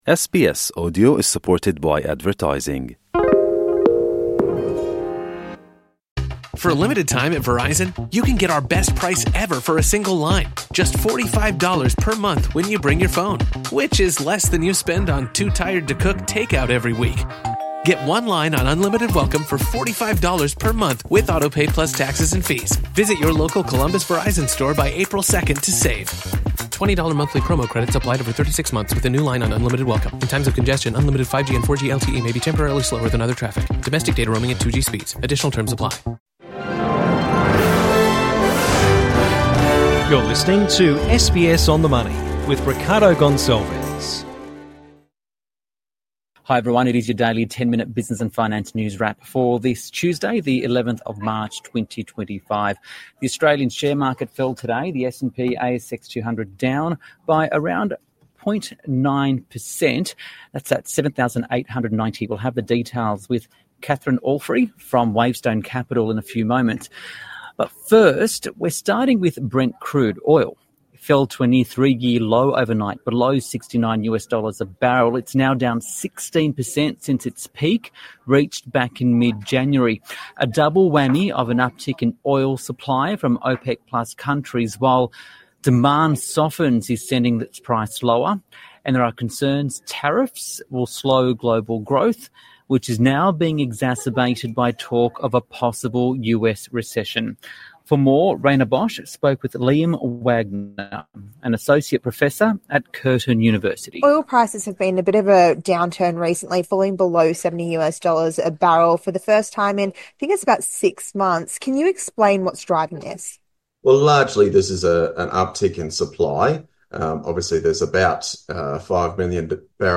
Your daily ten minute finance and business news wrap